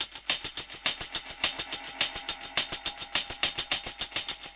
Index of /musicradar/dub-drums-samples/105bpm
Db_DrumKitC_Wet_EchoHats_105-03.wav